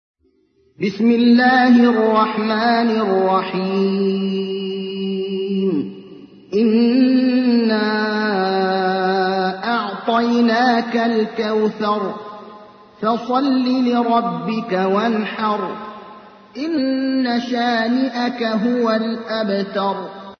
تحميل : 108. سورة الكوثر / القارئ ابراهيم الأخضر / القرآن الكريم / موقع يا حسين